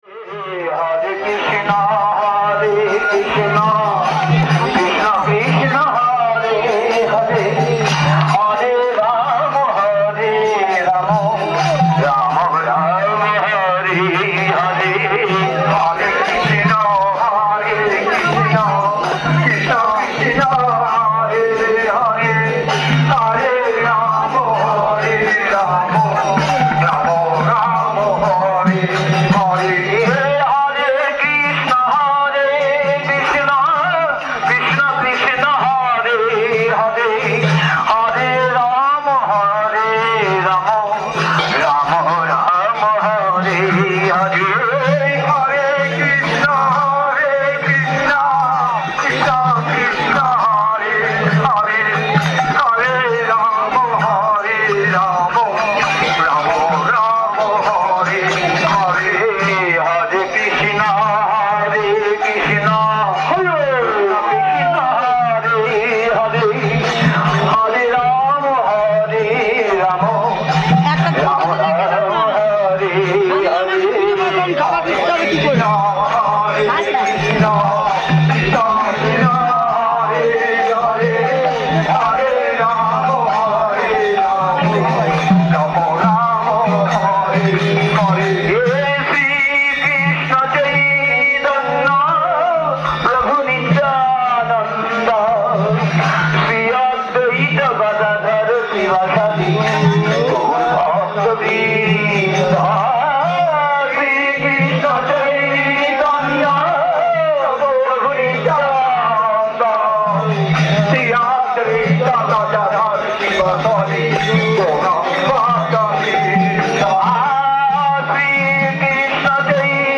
Place: SCSMath Nabadwip
Kirttan